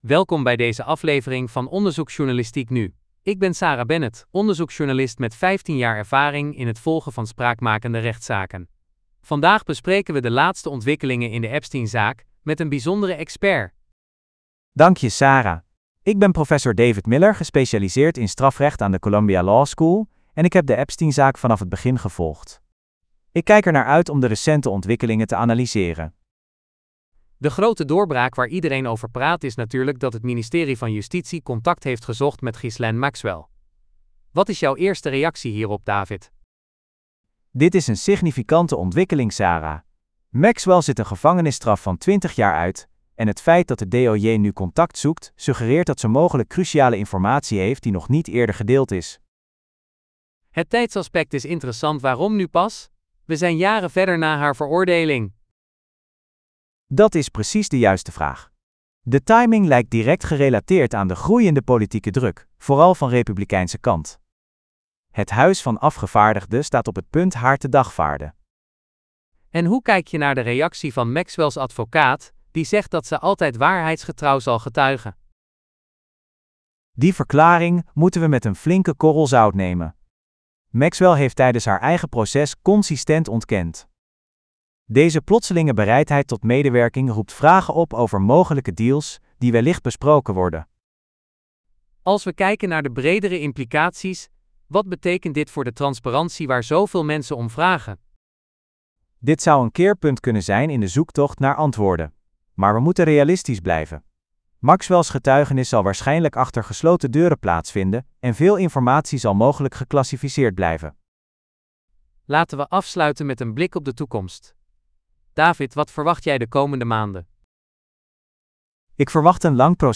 Podcast gegenereerd van tekst content (6835 karakters)